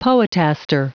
Prononciation du mot poetaster en anglais (fichier audio)
Prononciation du mot : poetaster